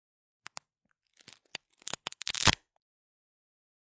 描述：一个人说着中文慢慢生活
声道立体声